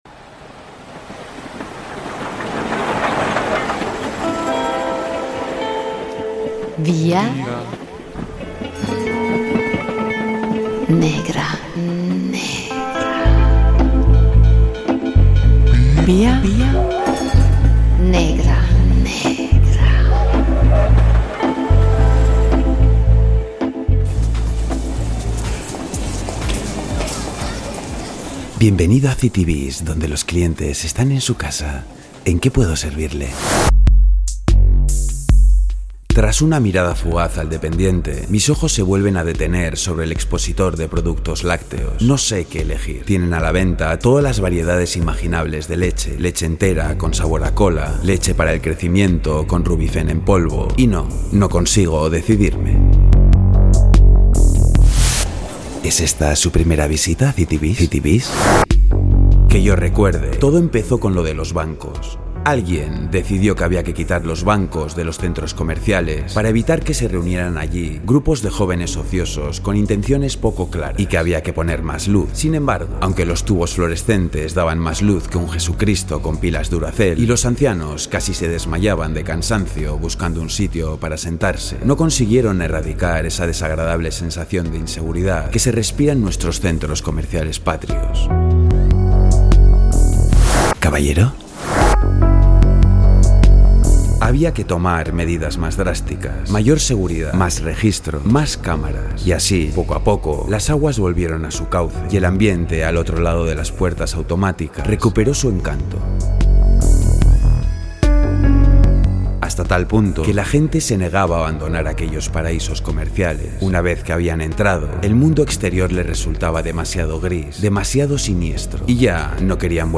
sonoriza en Vía Negra un fragmento de Por qué el mundo funciona perfectamente sin mi, del escritor flamenco Joost Vandecasteele
Radio Euskadi GRAFFITI "Date un capricho"